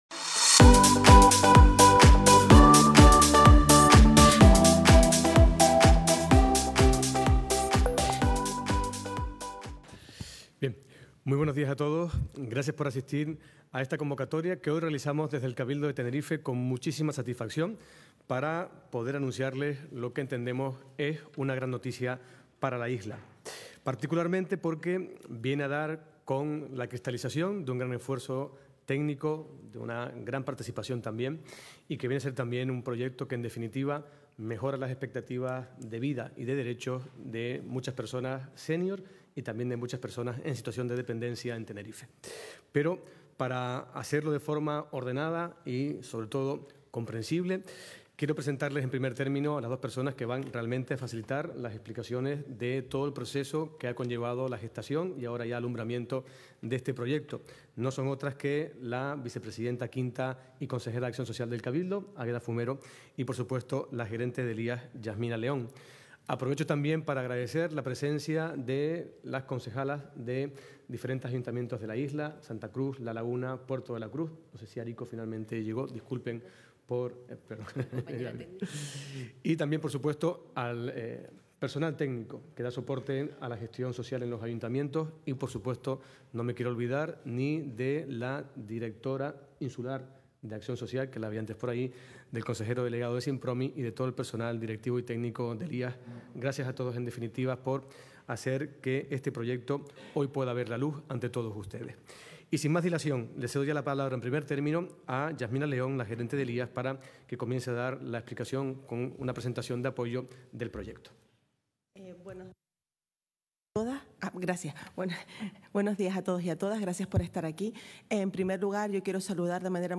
Presentación del nuevo Servicio Insular de Atención Integral para la permanencia en el Hogar de las Personas Mayores de Tenerife.